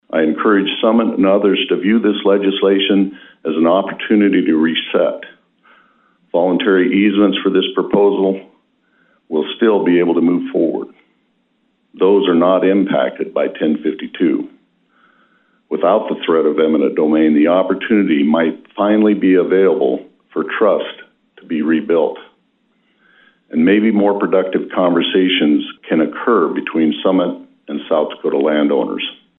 Governor Rhoden comments following the signing of HB1052